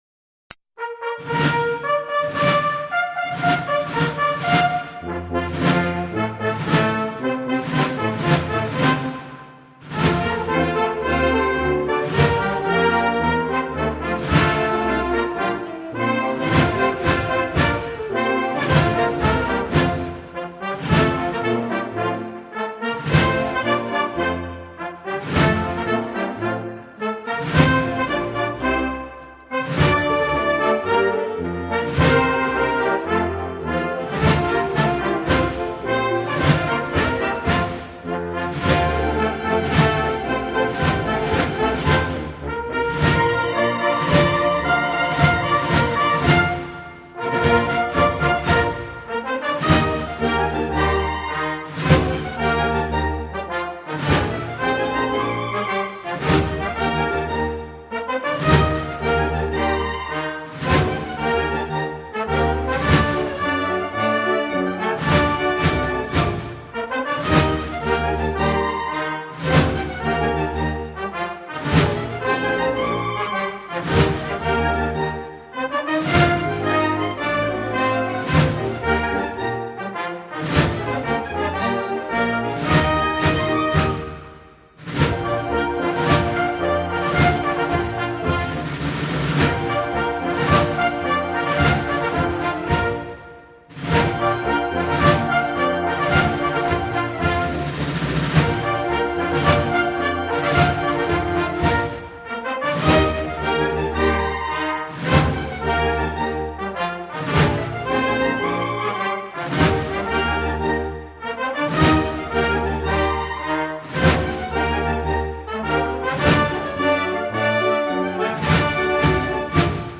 MARCHE-DES-SAPEURS-DU-GENIE.mp3